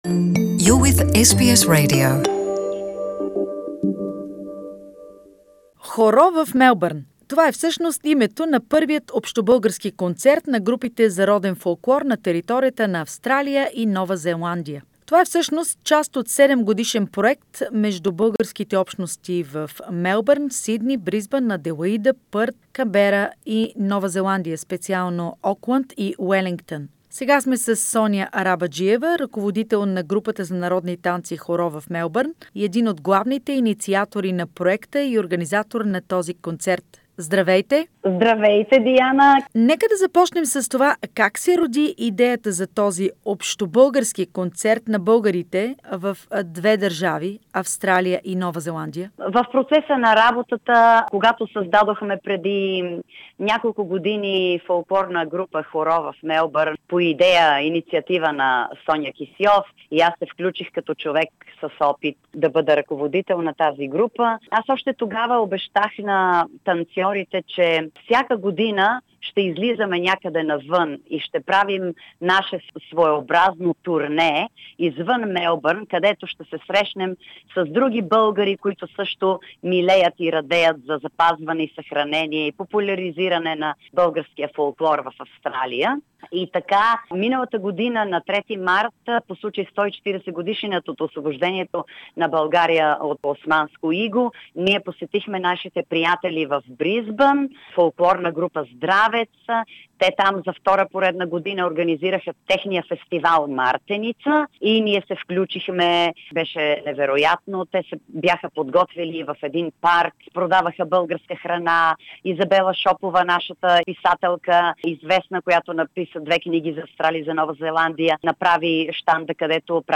"Horo Melbourne" is the first of the planned all-Bulgarian folklore concerts of groups for folk dances, songs, folk music in Australia and New Zealand - interview